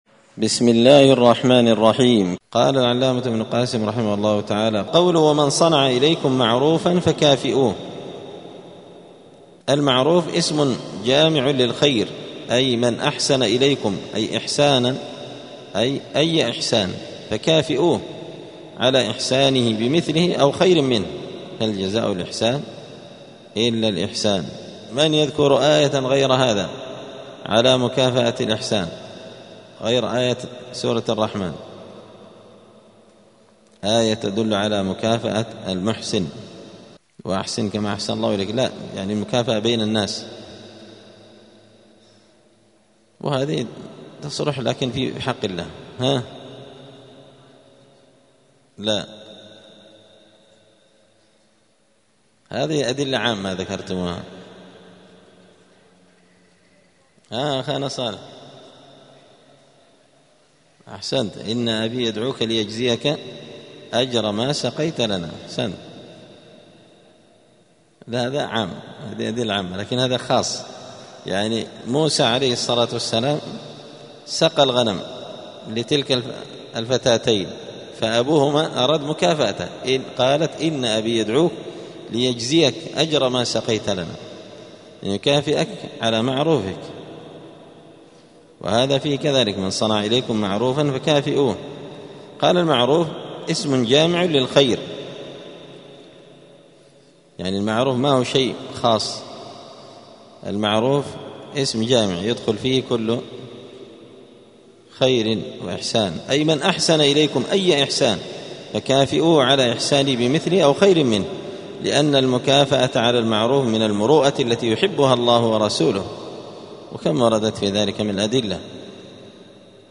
دار الحديث السلفية بمسجد الفرقان قشن المهرة اليمن
*الدرس الخامس والثلاثون بعد المائة (135) {باب لا يرد سأل بالله}*